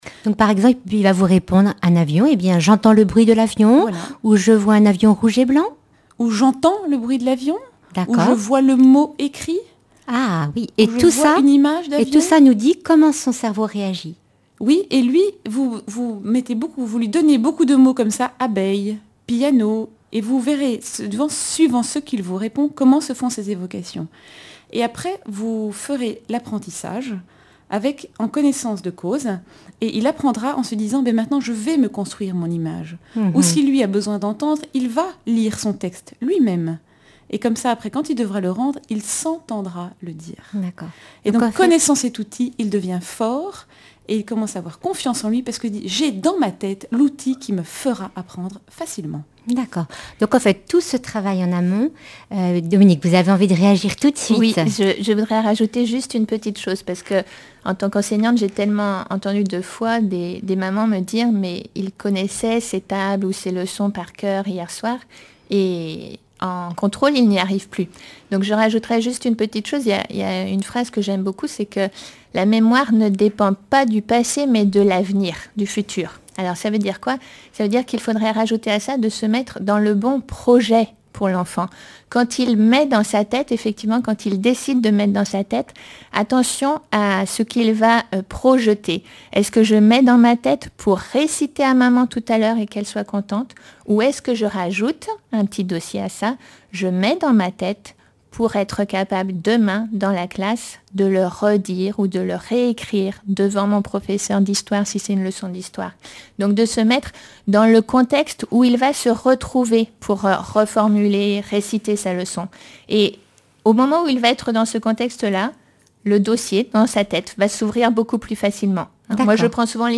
Emissions de la radio RCF Vendée
Catégorie Témoignage